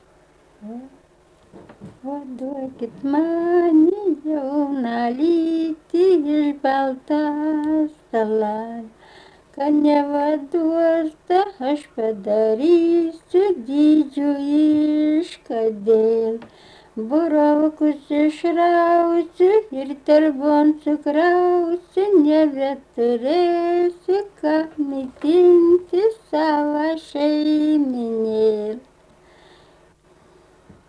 rauda
Jonava
vokalinis
Vestuvių raudos